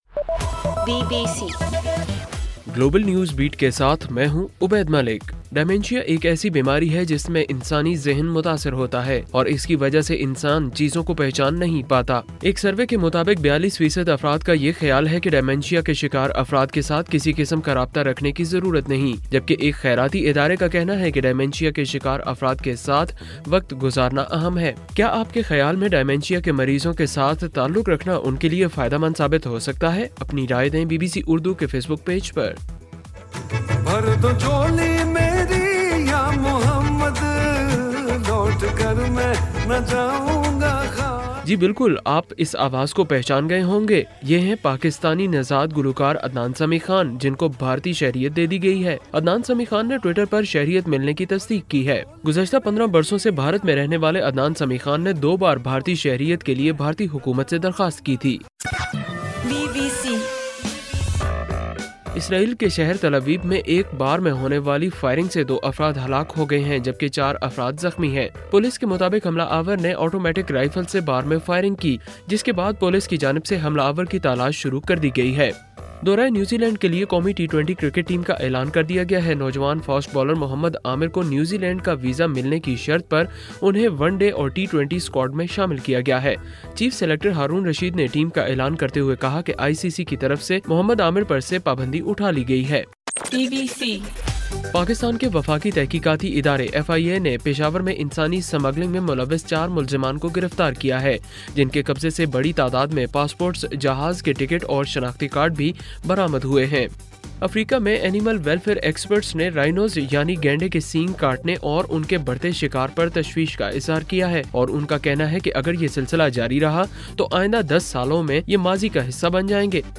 جنوری 01: رات 10 بجے کا گلوبل نیوز بیٹ بُلیٹن